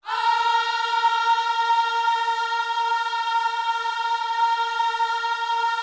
OHS A#4C  -L.wav